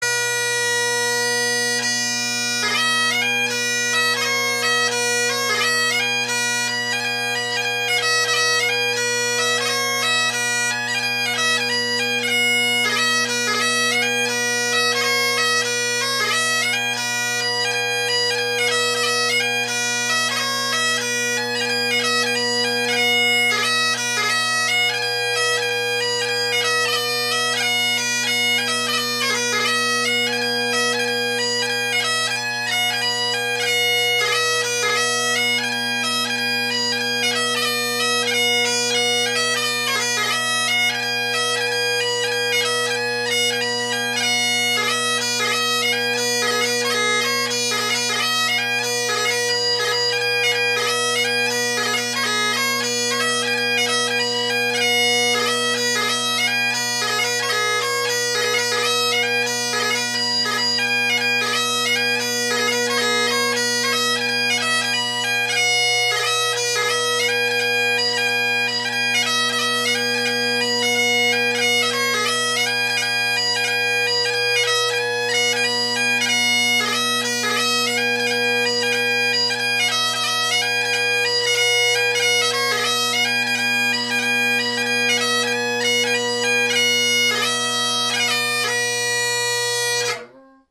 Below you’ll find 3 recordings, 2 with the drones pointed at the microphone and 1 chanter side to help you get a better idea of the relative volumes.
I am also currently working on blowing out the chanter reed more, so sometimes the high A is a little flat with some crow.
The Quaker – mic is chanter side
Drone Sounds of the GHB, Great Highland Bagpipe Solo